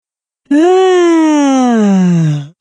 Clash Royale Yawning Emote Soundboard: Play Instant Sound Effect Button